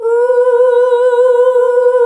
UUUUH   B.wav